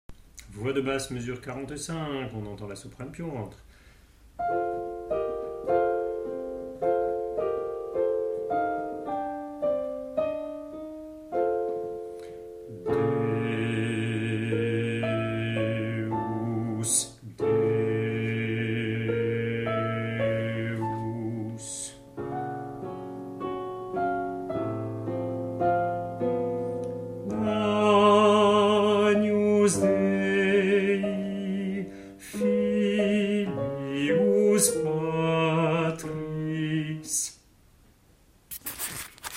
Les fichiers mp3 sont deux fois plus gros que les fichiers wma, et nécessairement de moins bonne qualité sonore.